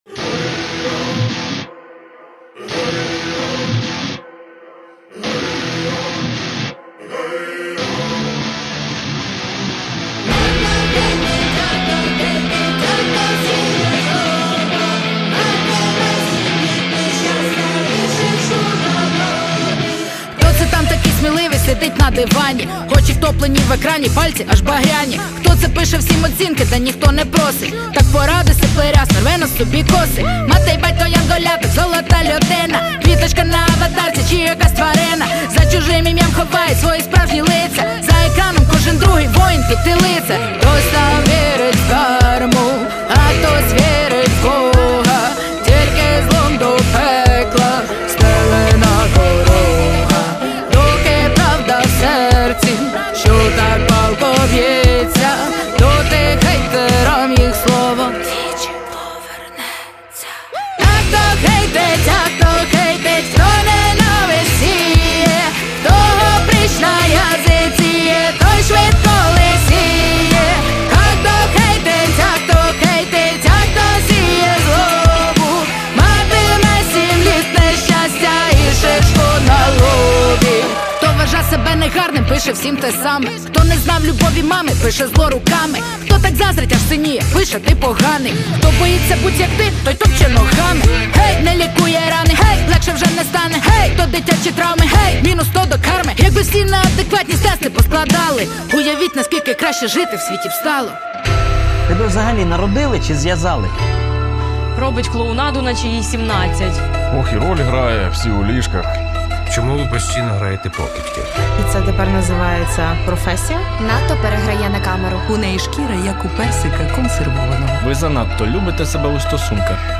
Жанр: Реп / хіп-хоп